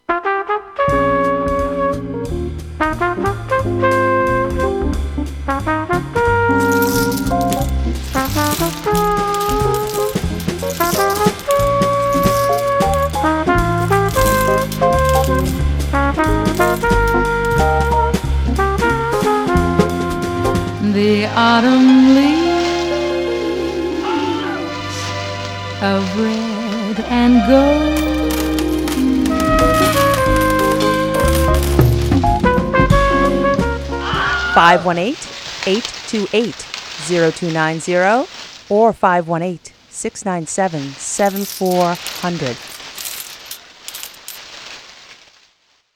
Fall Pledge 2013 Drive Sound Collage (Audio)